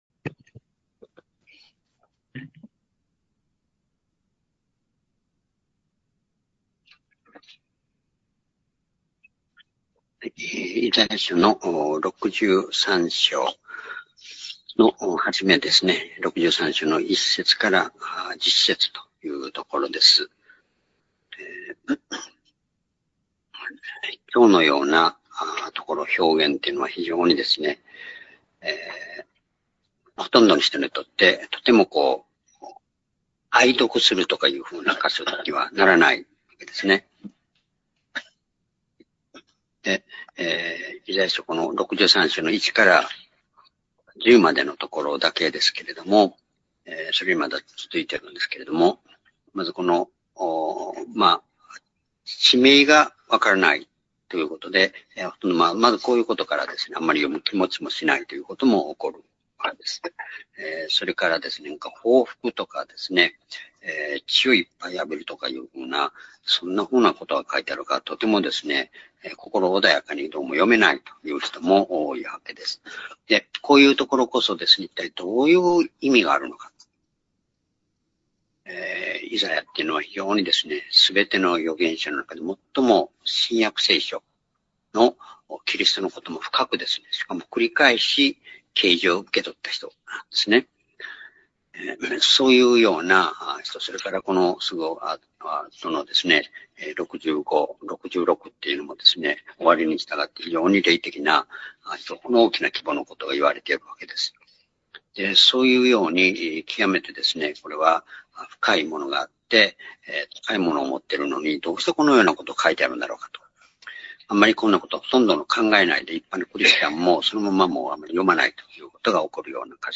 「神の愛とさばき」8-イザヤ63章1節～12節-２０２５年５月１１日（主日礼拝）